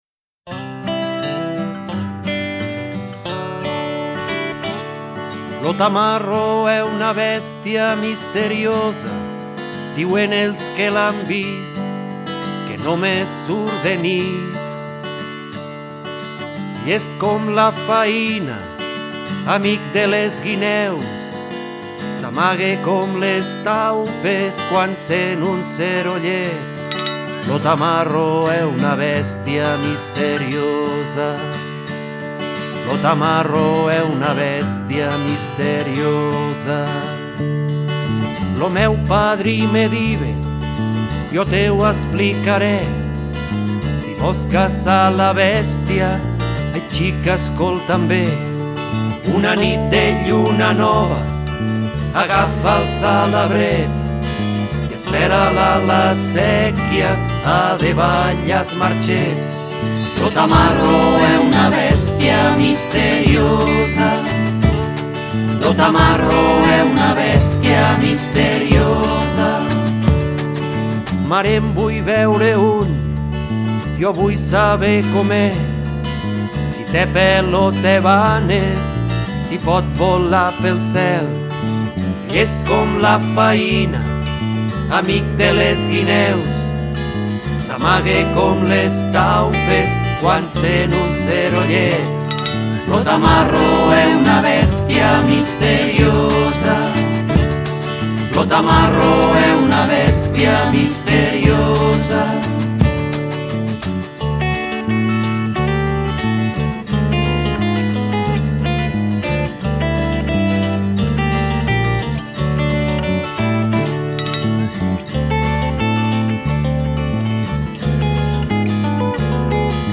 CD per nens
veu
percussiò